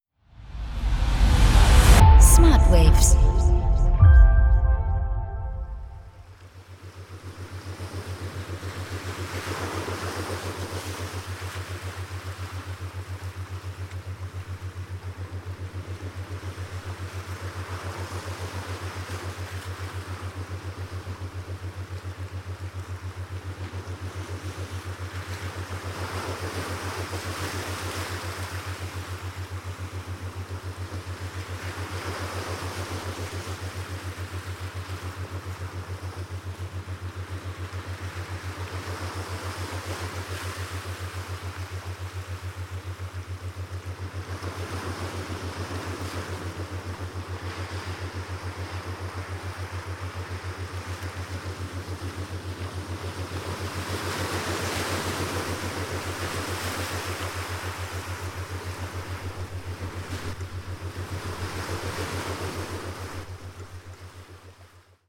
OCEAN WAVES
Ocean_Waves_snip.mp3